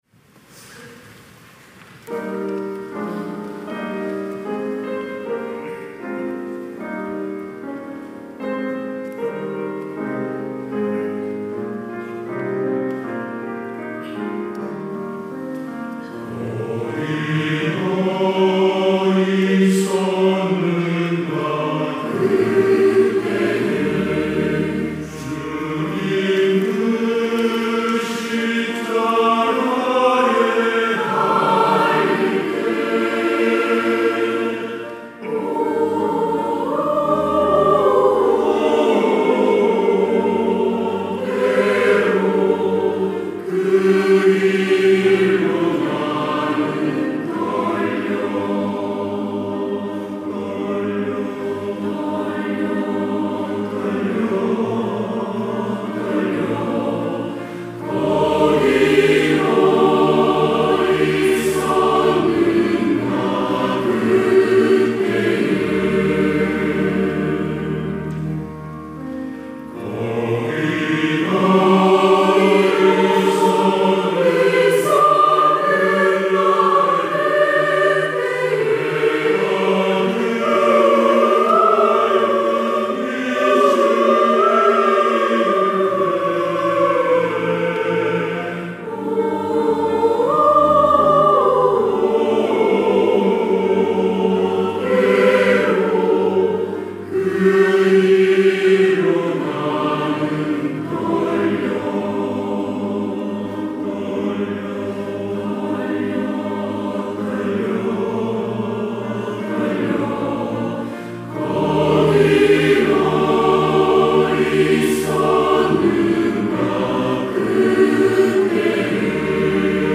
시온(주일1부) - 거기 너 있었는가
찬양대